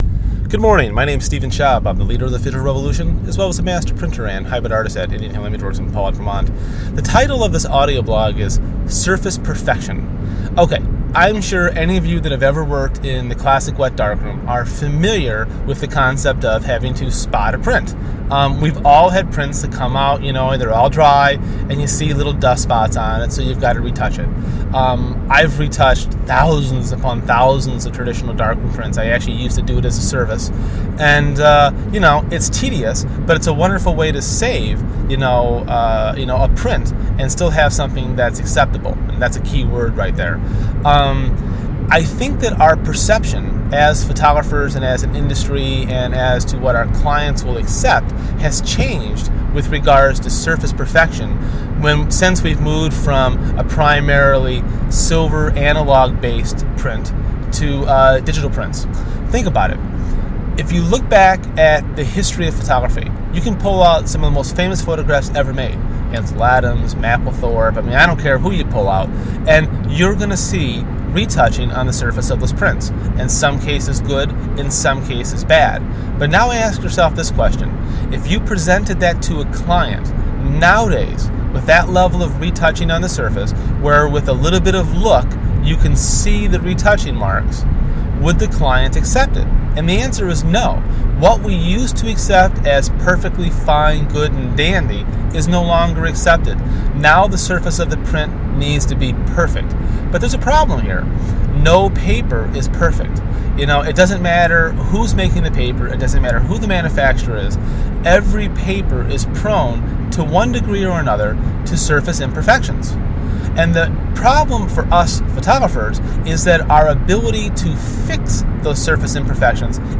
A quick audio blog on how our expectations of a perfect print surface has changed from traditional wet darkroom spotted prints to todays digital prints.